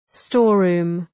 Shkrimi fonetik {‘stɔ:r,ru:m}